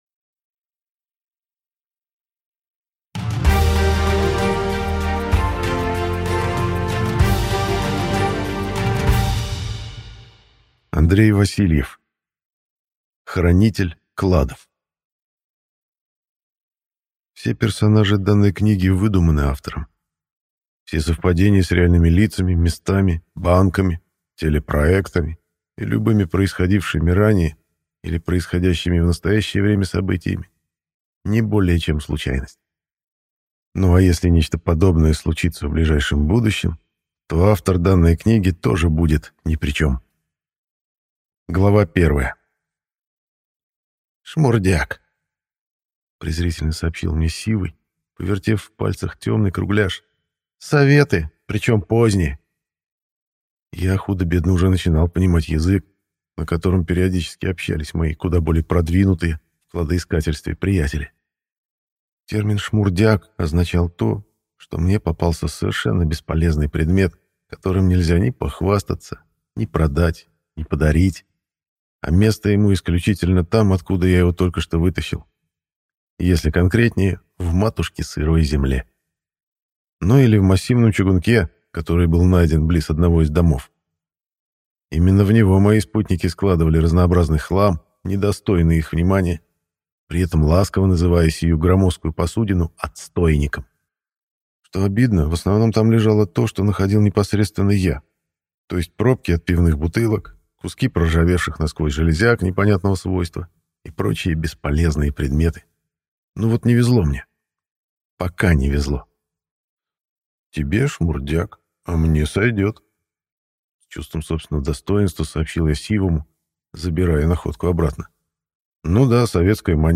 Аудиокнига Хранитель кладов | Библиотека аудиокниг
Прослушать и бесплатно скачать фрагмент аудиокниги